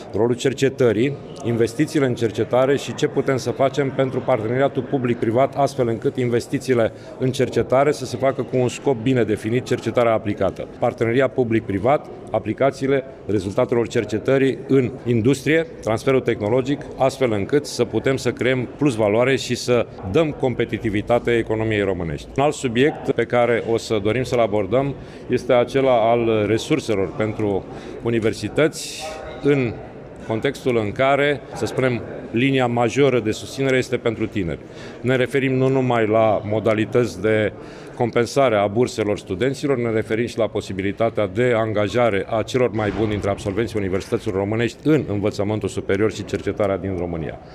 La deschiderea lucrărilor Consiliului Național al Rectorilor, președintele forului, profesorul doctor Sorin Cîmpeanu, a declarat că temele principale ale reuniunii vizează resurele financiare pentru tineri, cercetare și transfer tehnologic.